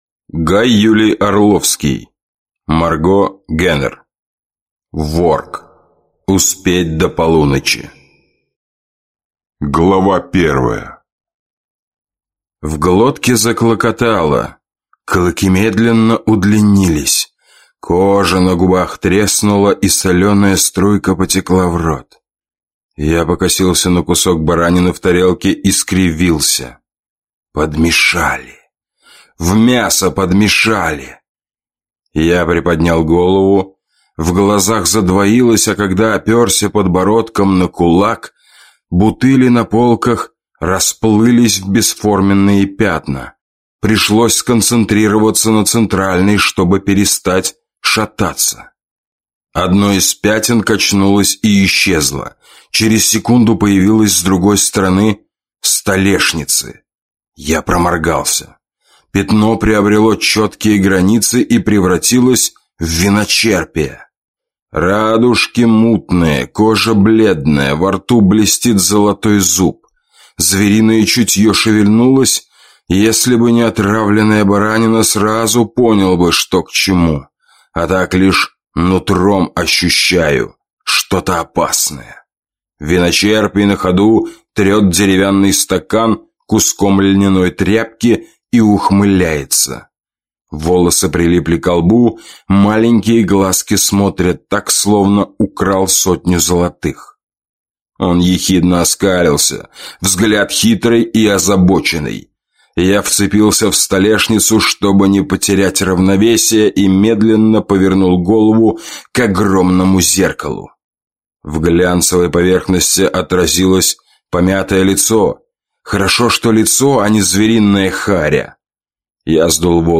Аудиокнига Ворг. Успеть до полуночи | Библиотека аудиокниг